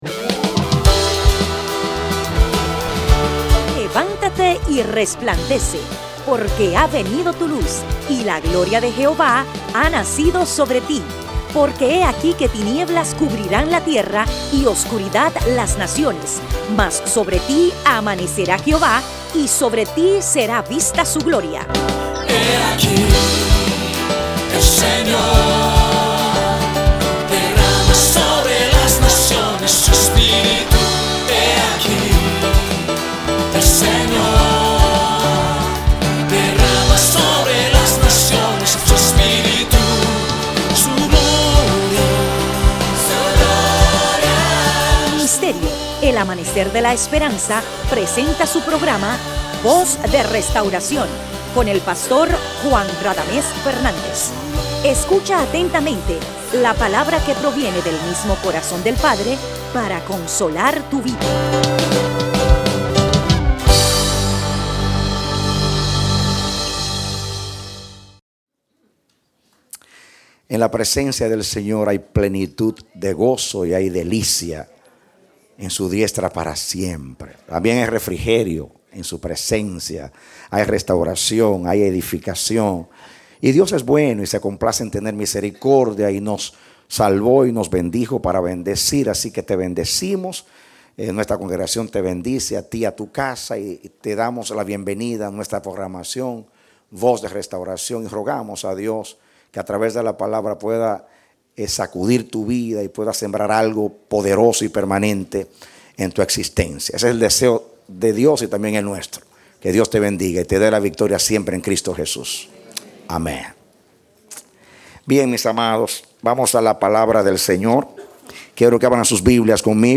Parte A Predicado Junio 2, 2013